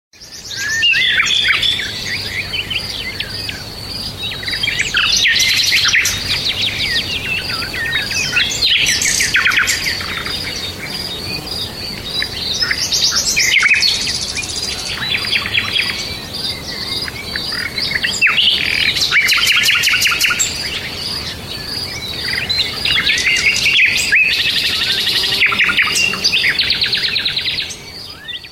nature-bird-sounds.mp3